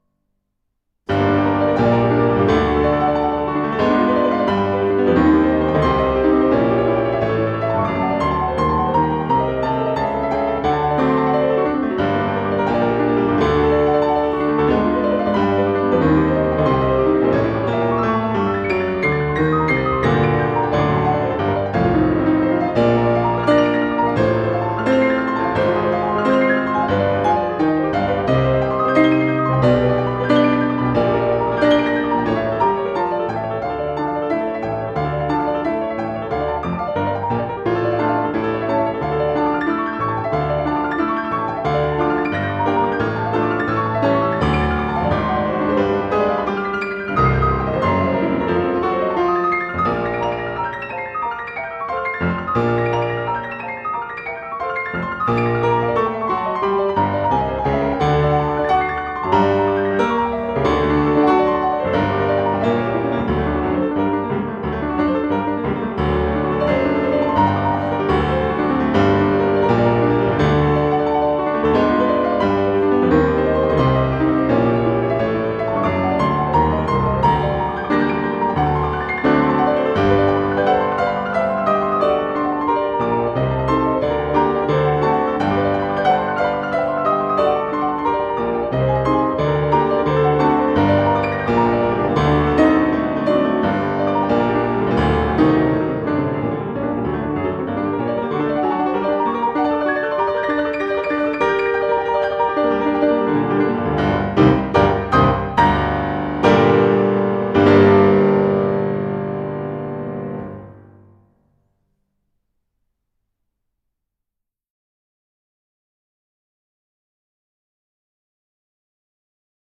piano bleu